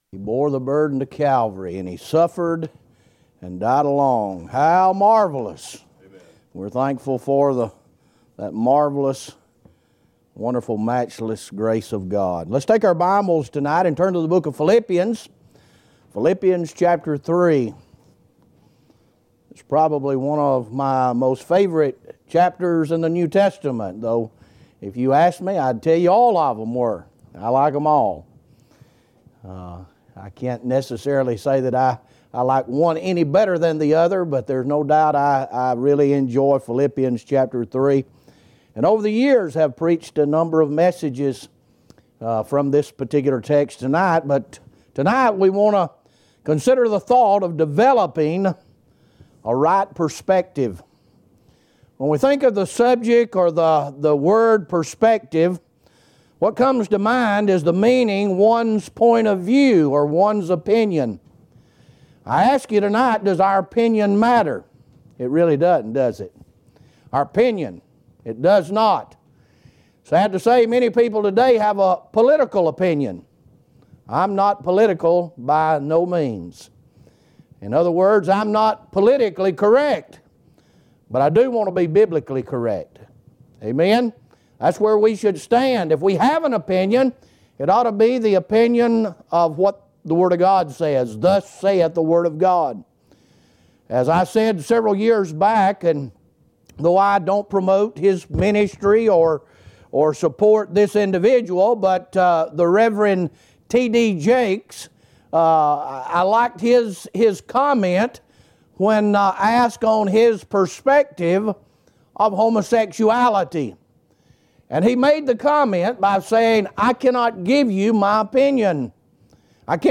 Evening Sermon